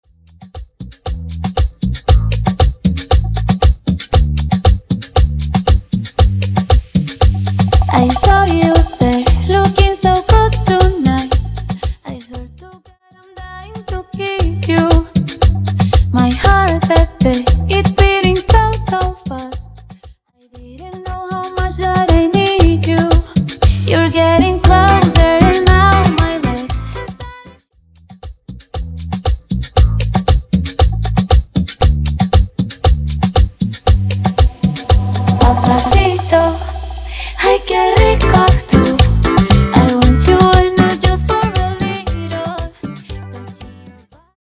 Intro Acapella Dirty, Pro Coro Dirty